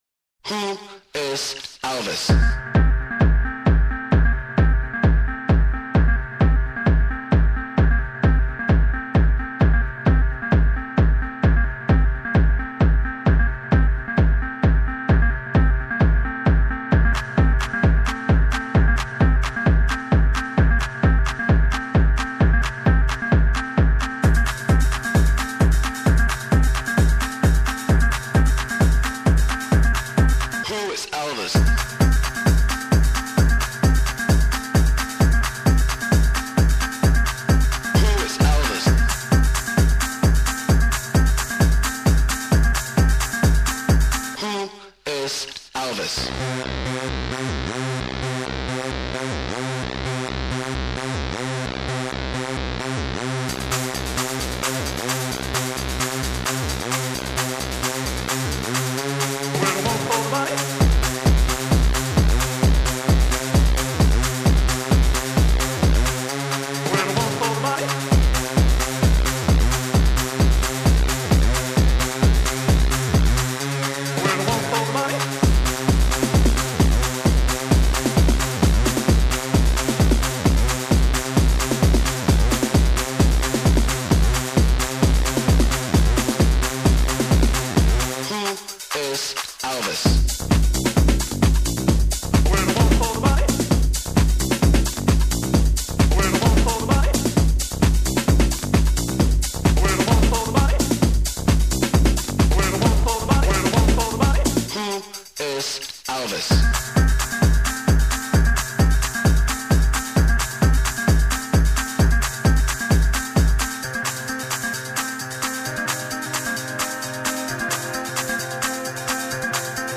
Un mix 100% generation 90 !